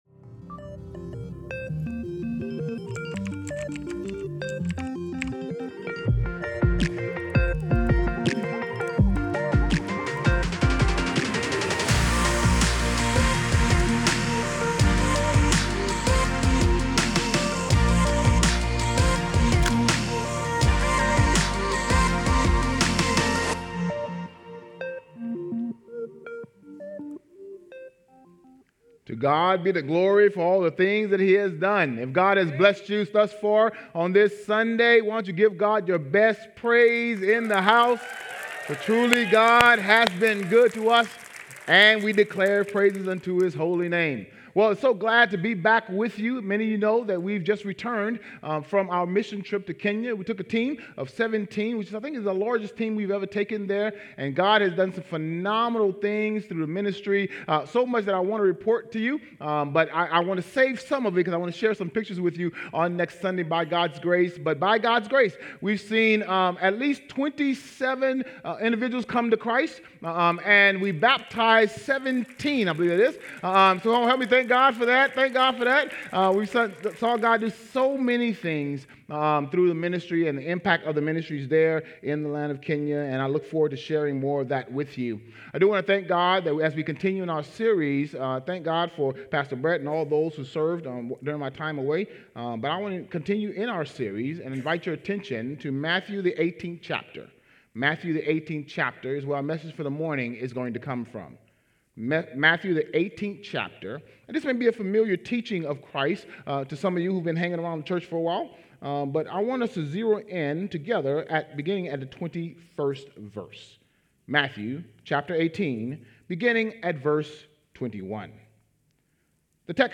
JV-SERMON-ENG-5.12.24-aUDIO.mp3